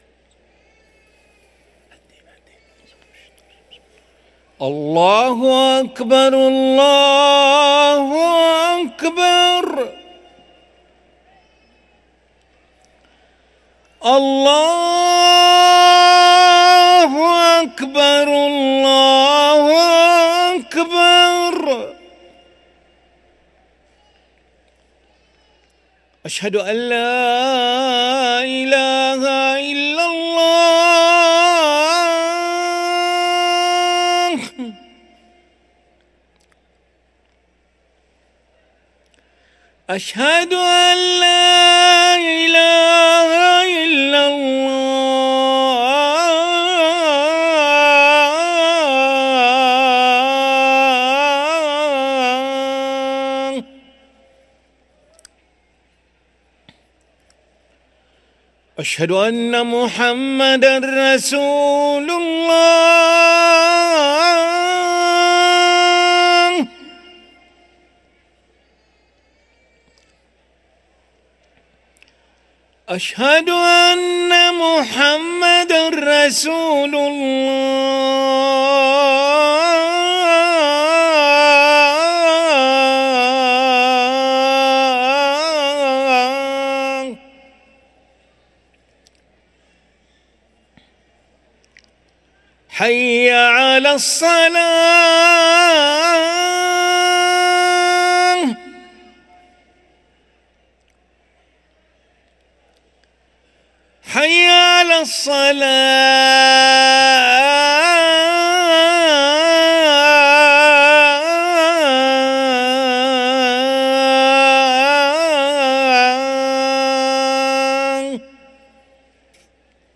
أذان العشاء للمؤذن علي ملا الخميس 11 ربيع الآخر 1445هـ > ١٤٤٥ 🕋 > ركن الأذان 🕋 > المزيد - تلاوات الحرمين